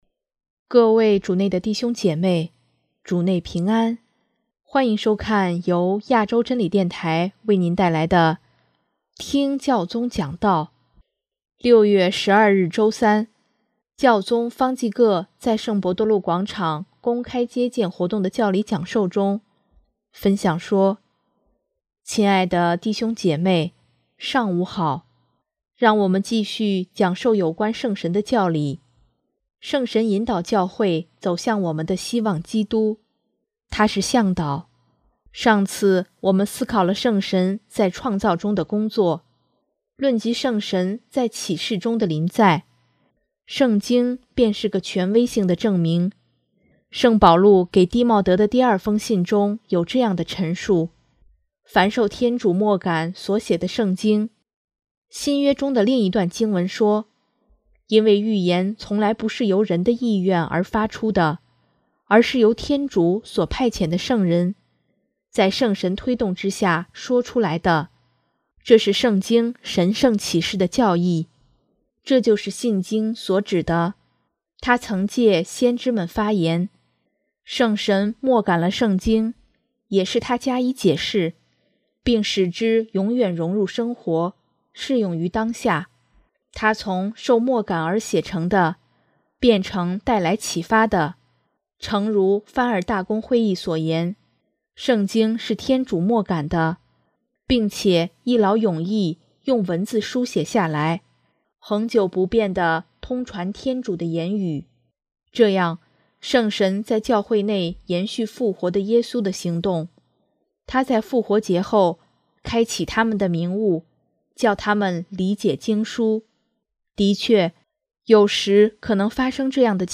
6月12日周三，教宗方济各在圣伯多禄广场公开接见活动的教理讲授中，分享说：